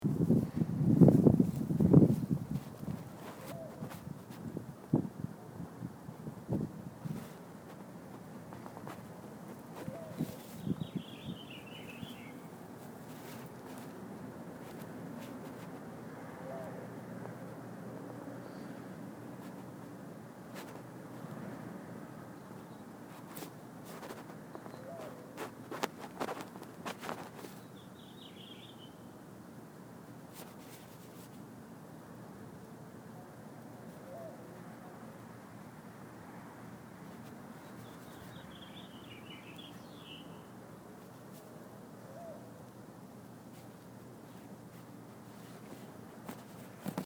Walking from Subway to my building
Swipe cards, doors sliding open, trunstile unlocking, voices